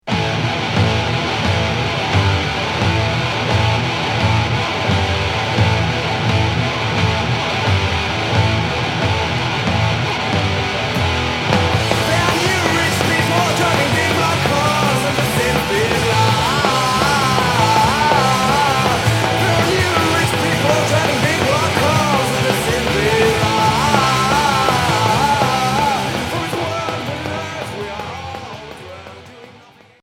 Hardcore